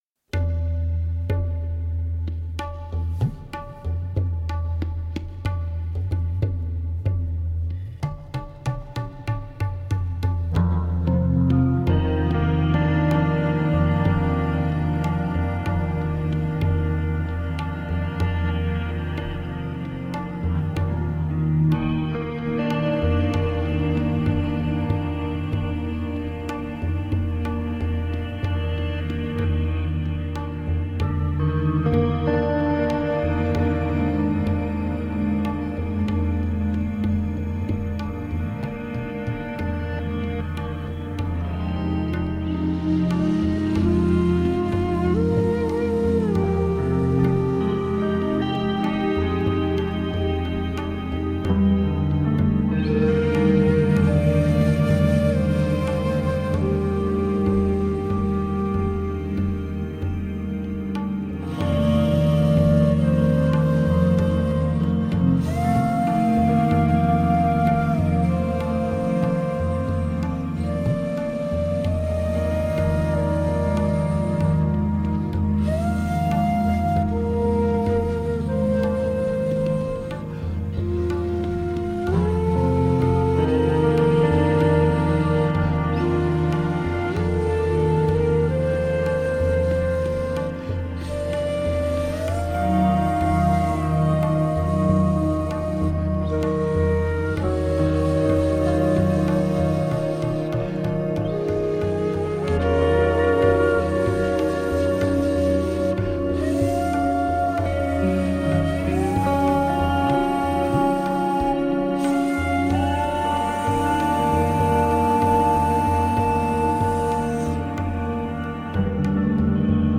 Beautiful ambient space music.
Tagged as: New Age, Ambient